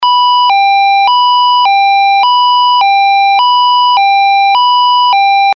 Sirena electrónica